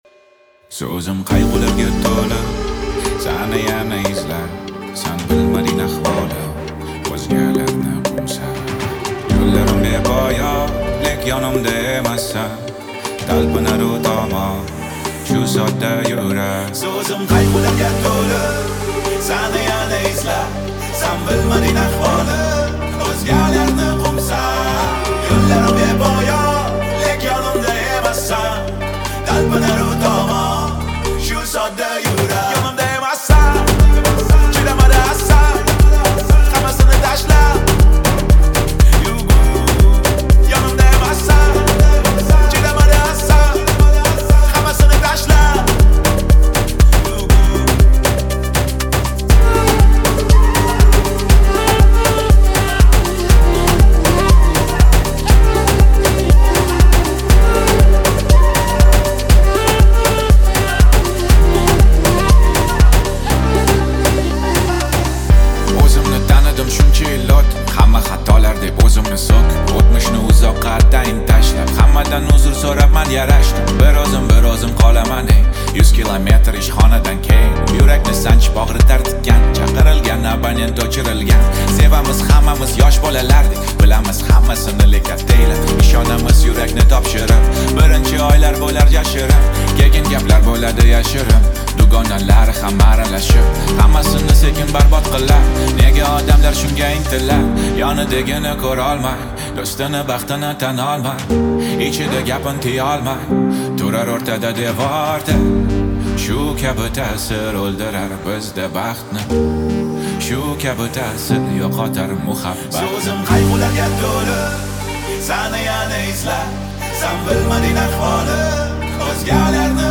узбекская музыка в формате mp3.